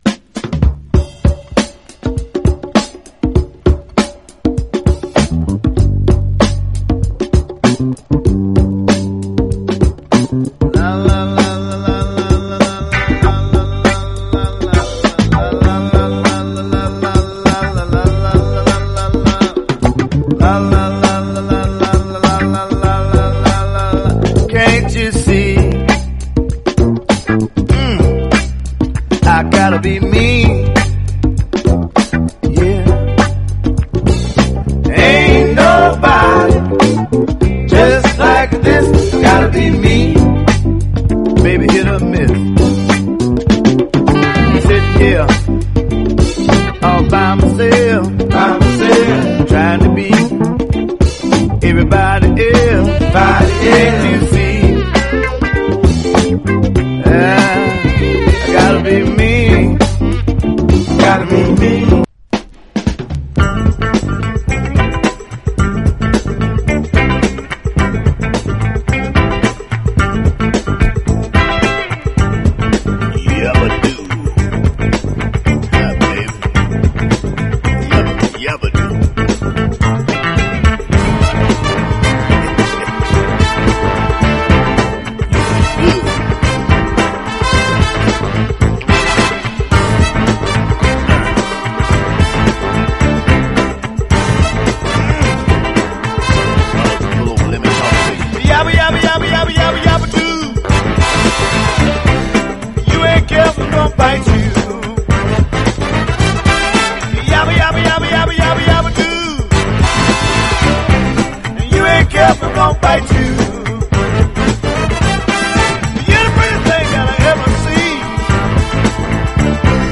ハモンド・オルガンとベースが唸る腰に来るナンバーがギッシリ！